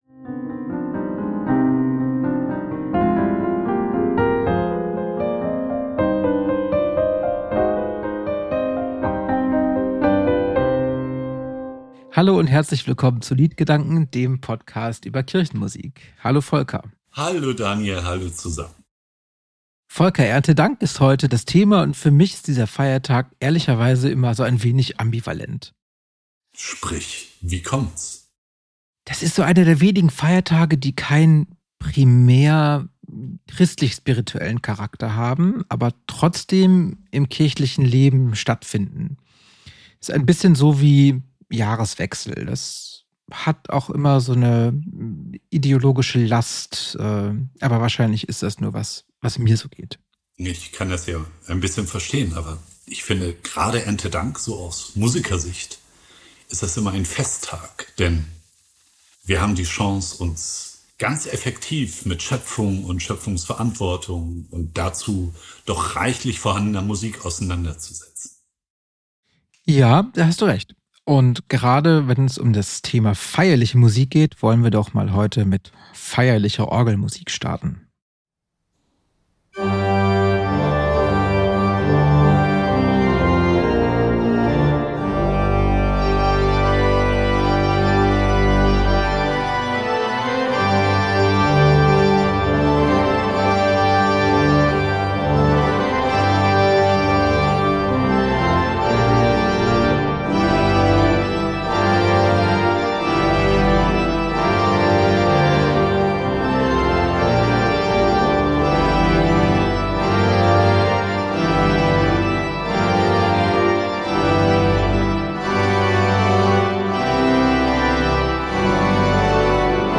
Orgel